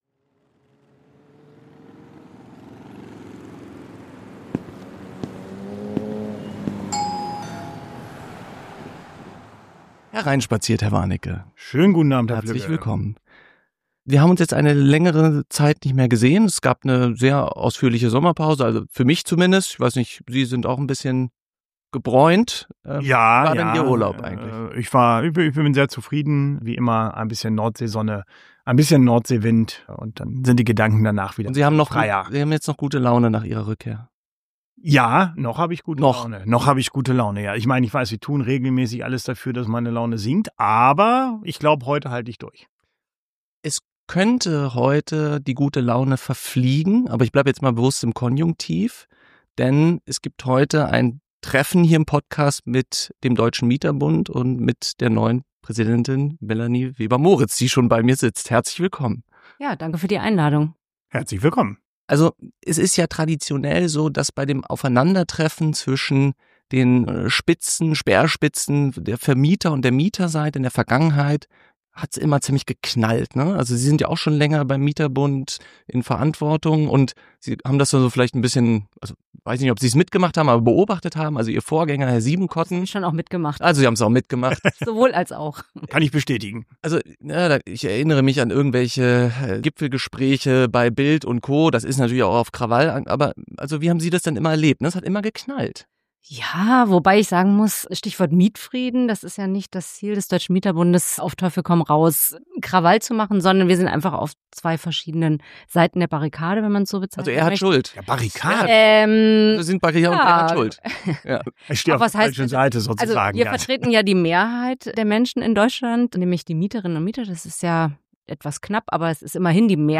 Ein Gespräch der Gegensätze – nicht nur inhaltlich.